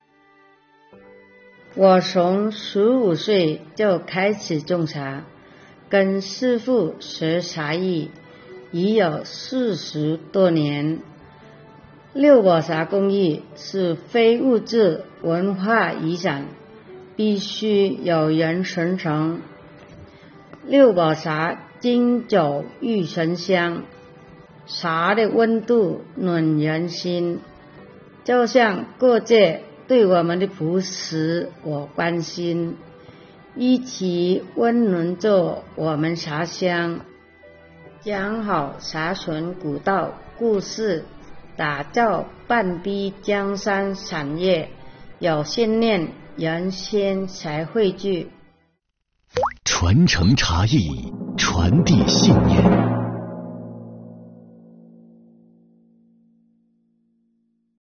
税收公益广告丨《茶艺人》